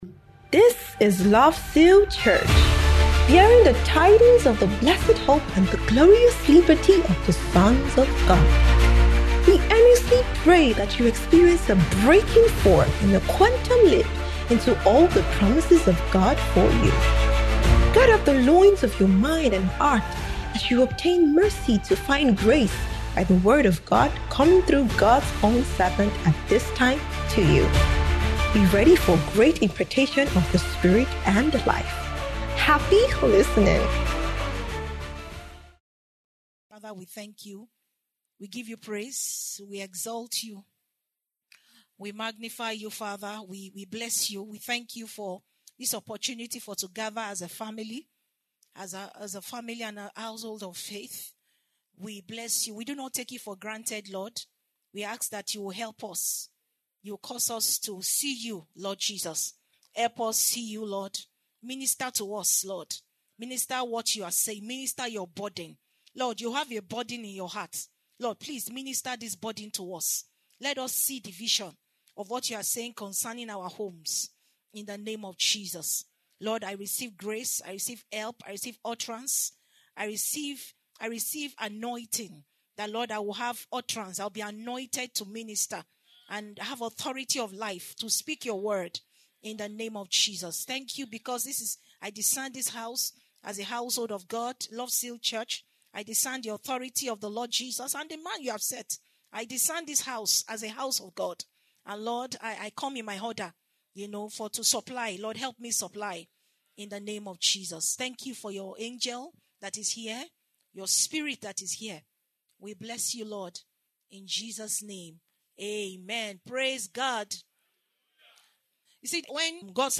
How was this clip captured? Kingdom Believers’ Community Service